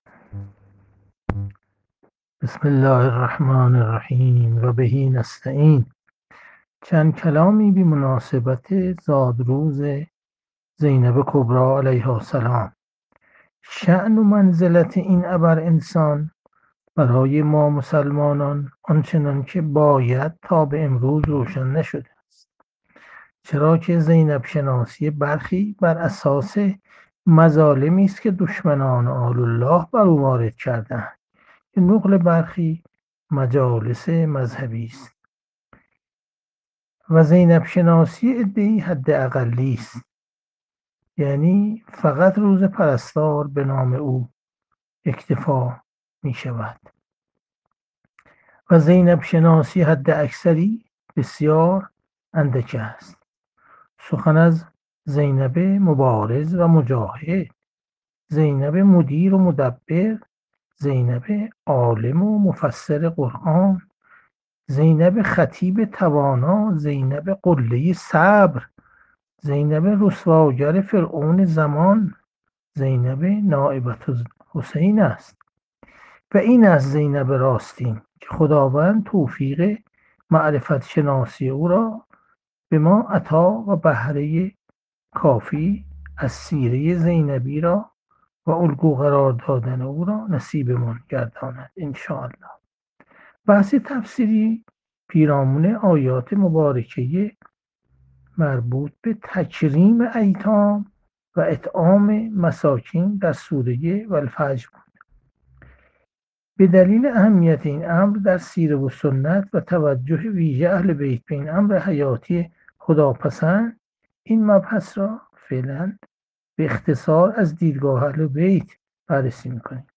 جلسه مجازی هفتگی قرآنی،سوره فجر، 21 آذر 1400
• تفسیر قرآن